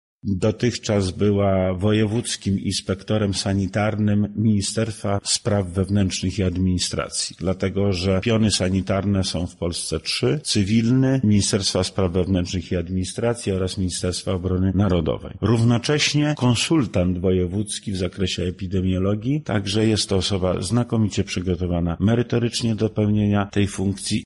Wojewoda Lubelski, Lech Sprawka tłumaczy dlaczego właśnie Korniuszuk objęła tę funkcję: